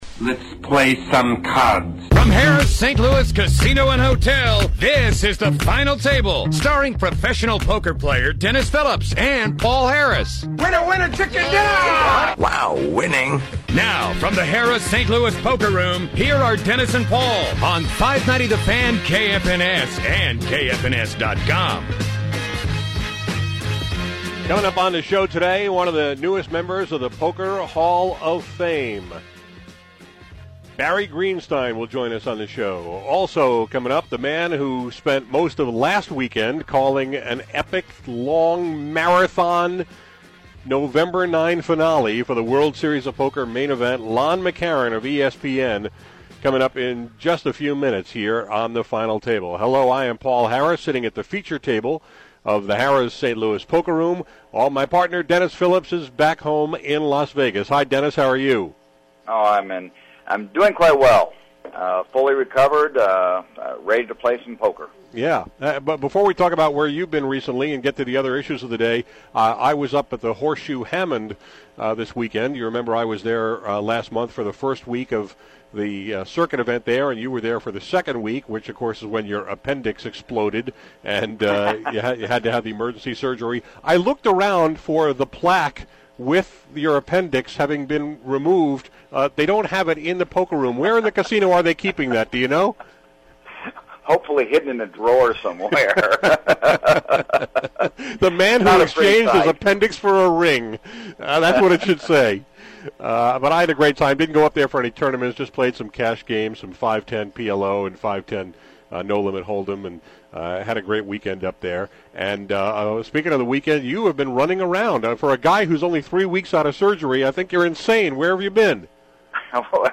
Today on The Final Table poker radio show, we welcomed back Lon McEachern, the voice of ESPN’s World Series Of Poker Main Event broadcasts, and Barry Greenstein, the newest member of the Poker Hall Of Fame.